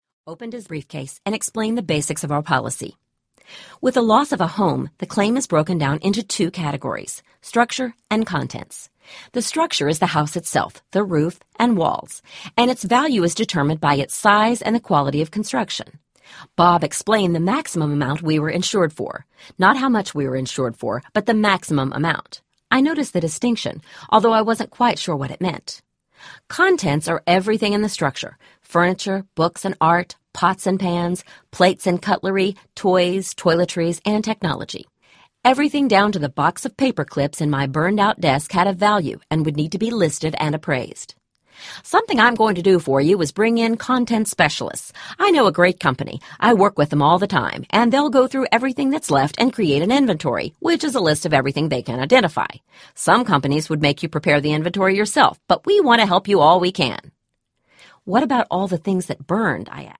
The Pug List Audiobook
6.03 Hrs. – Unabridged